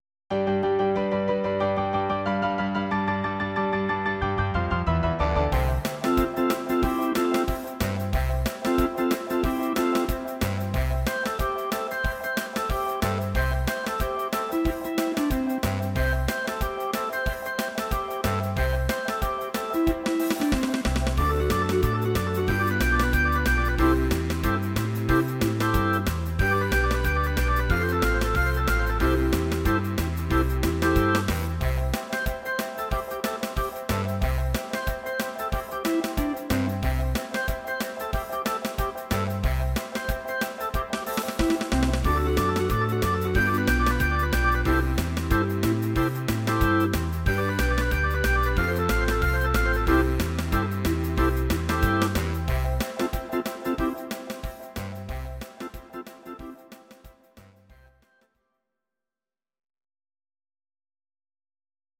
Audio Recordings based on Midi-files
Pop, Oldies, Instrumental, 1950s